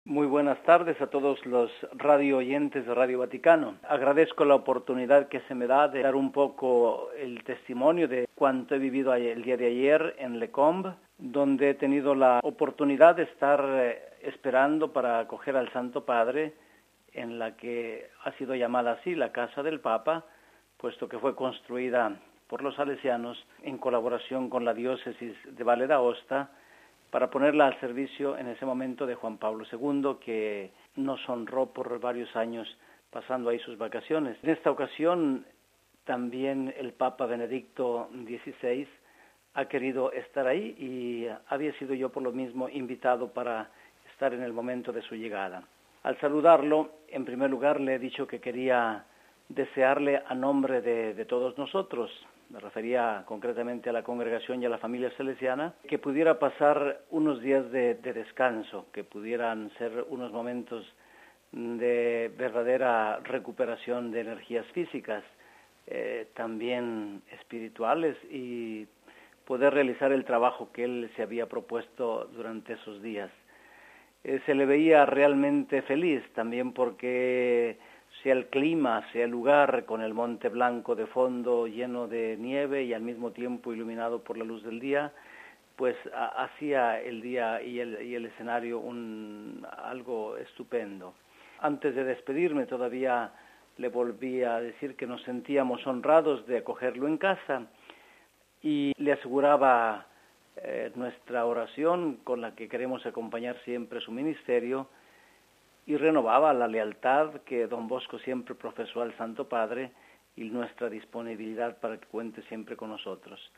Tenemos ante nuestros micrófonos, precisamente a don Pascual Chávez, que nos ofrece su testimonio sobre la bienvenida que le dio al Papa: RealAudio